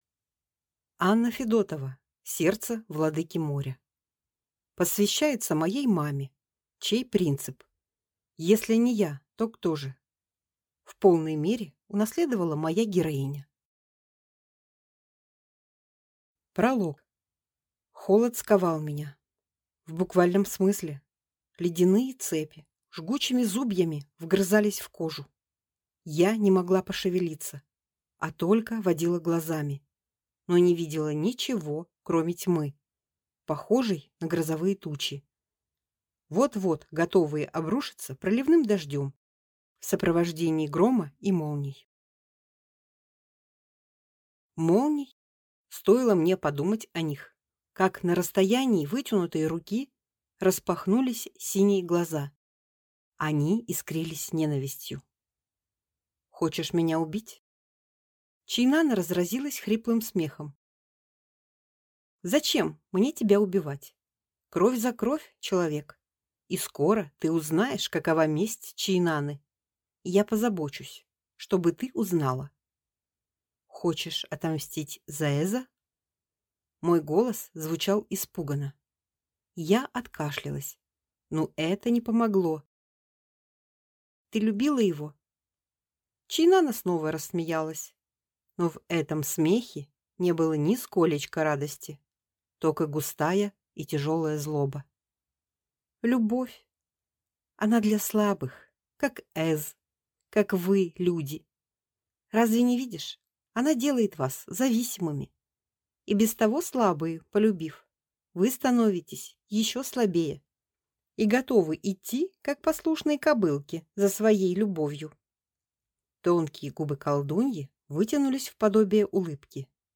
Аудиокнига Сердце владыки моря | Библиотека аудиокниг